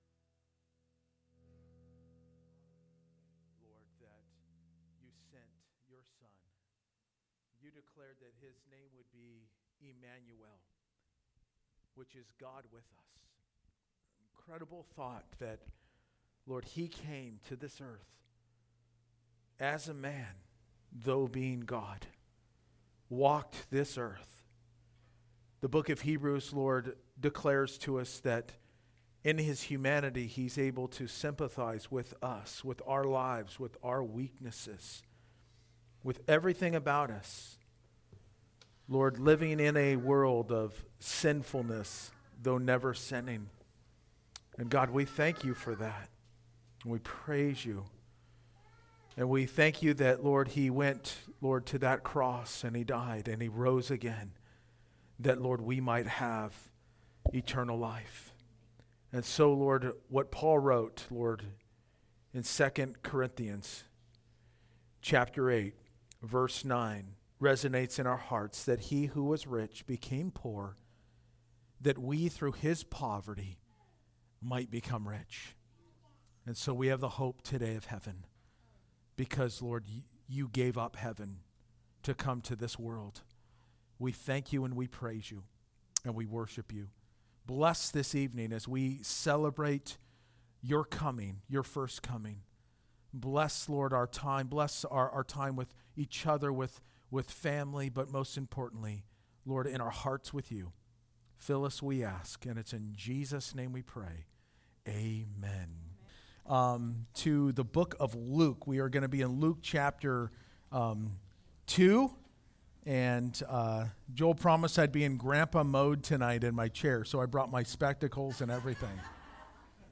Candlelight Christmas Eve Service 2018, Luke 1 & 2